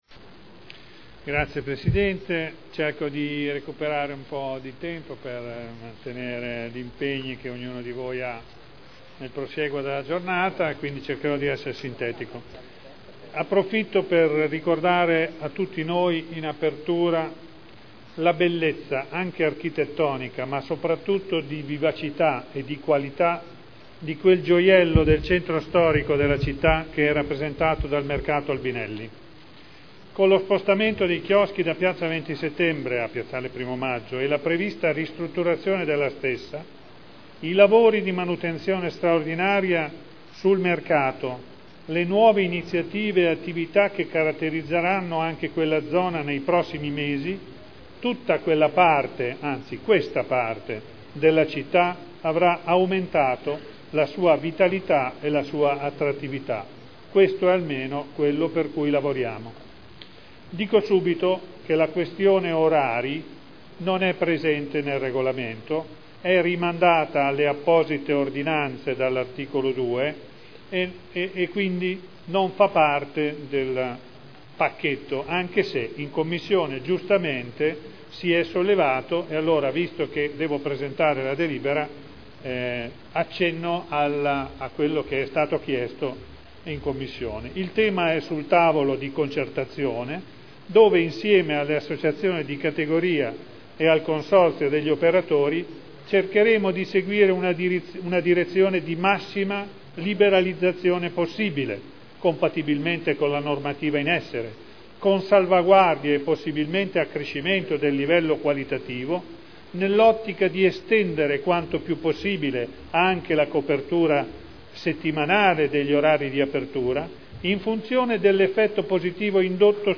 Graziano Pini — Sito Audio Consiglio Comunale